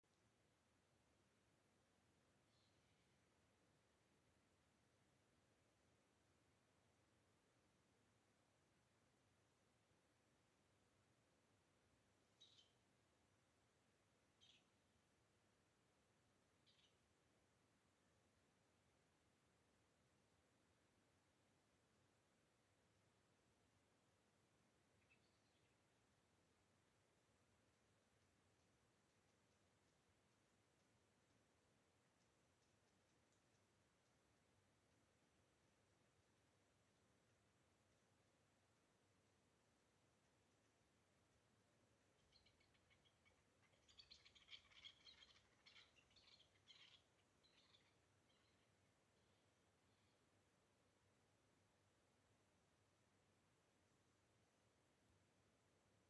Grey Partridge, Perdix perdix
StatusVoice, calls heard